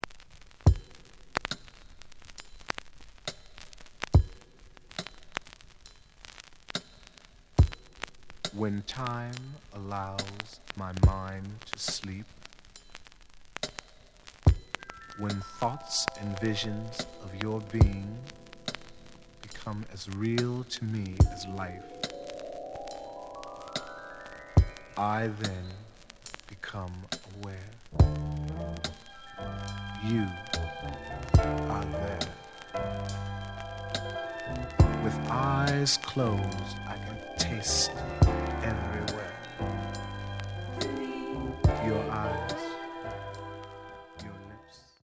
The audio sample is recorded from the actual item.
●Genre: Funk, 70's Funk
Slight damage on both side labels. Edge warp.